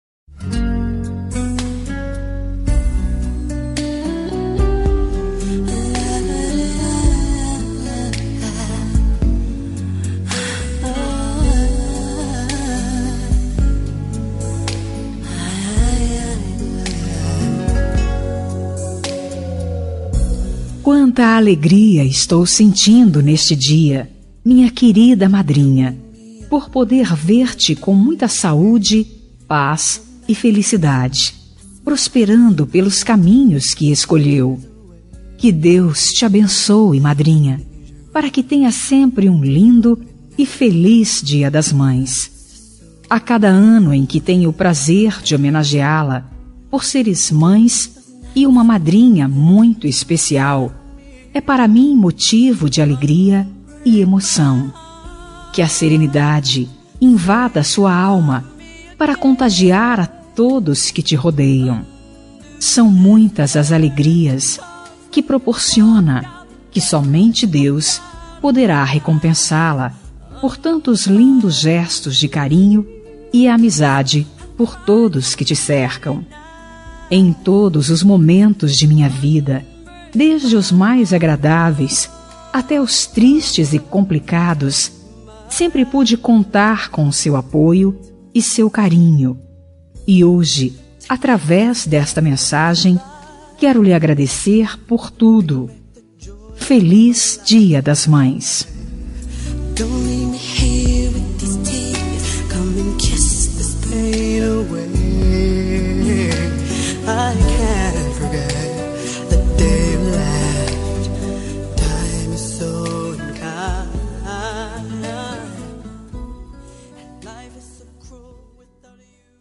Dia das Mães – Consideração Madrinha – Voz Feminina – Cód: 6806